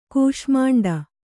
♪ kūṣmāṇḍa